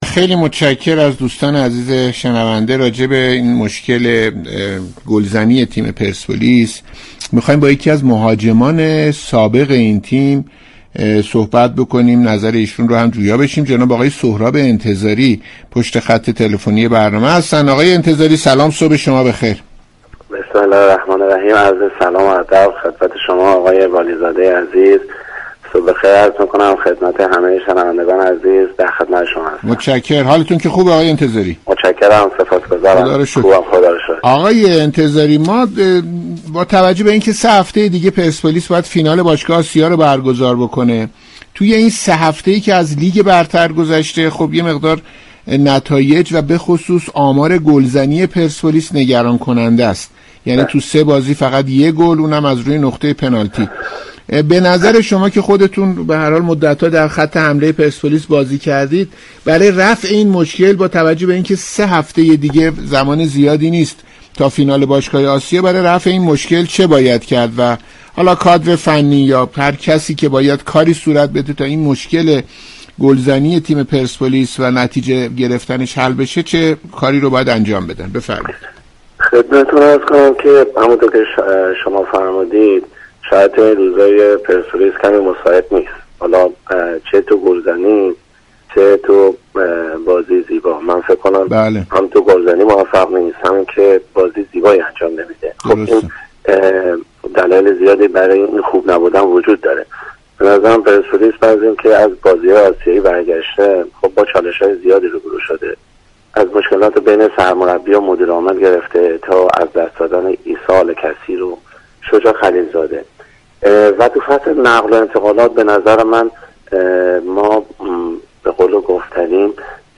شما می توانید از طریق فایل صوتی پیوست بطور كامل شنونده این گفتگو باشید.